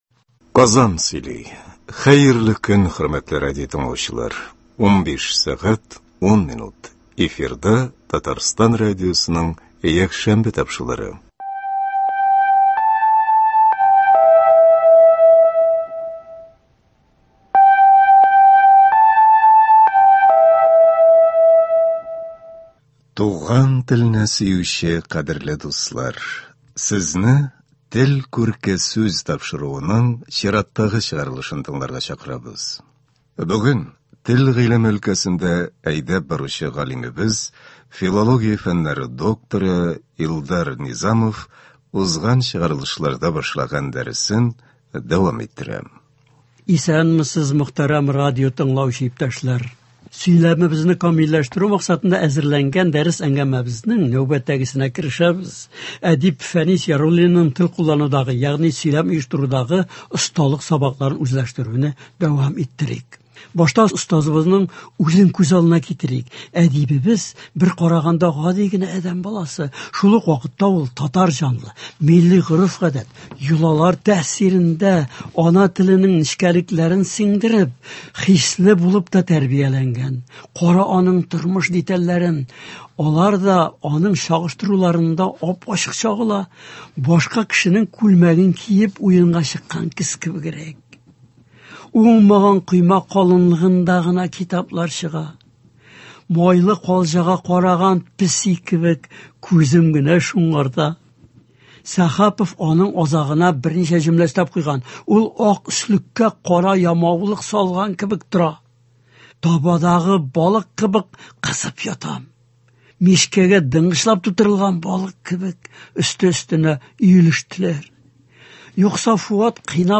Ахырда, хрестоматия рәвешендә, “Урман кызы” әсәреннән өзек бирелә.